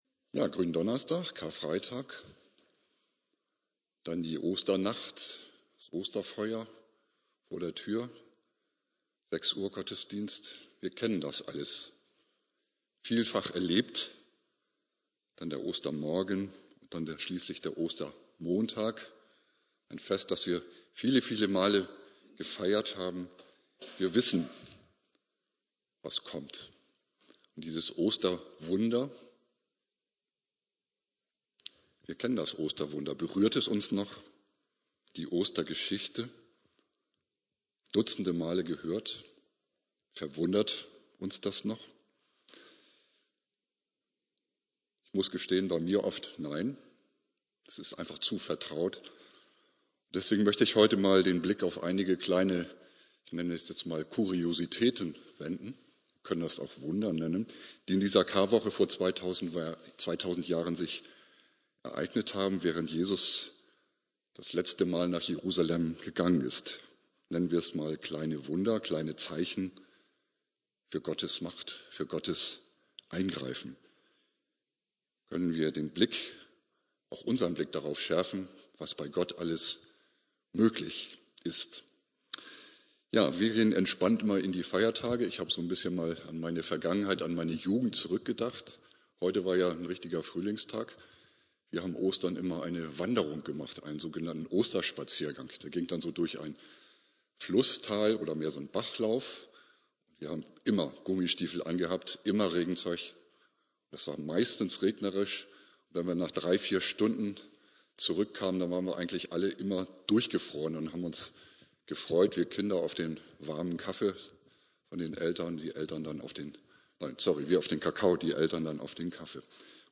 Predigt
Gründonnerstag